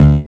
13SYN.BASS.wav